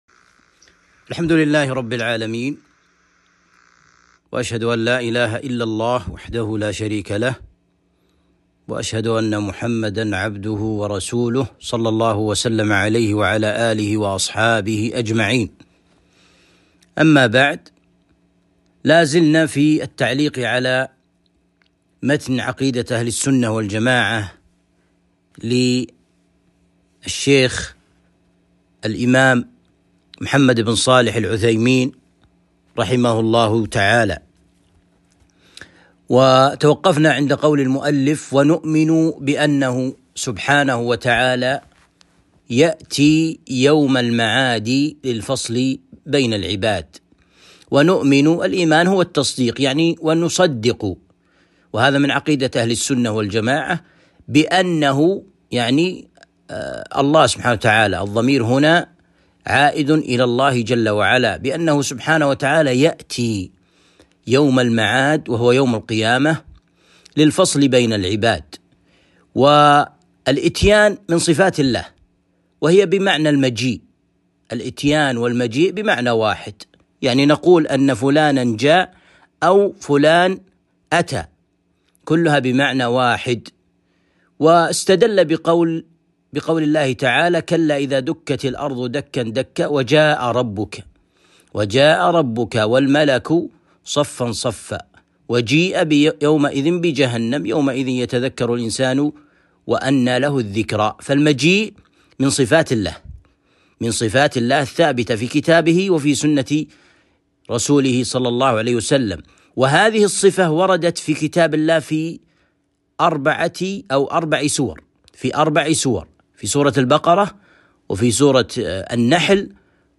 الدرس الثالث - شرح عقيدة اهل السنة والجماعة - الشيخ ابن عثيمين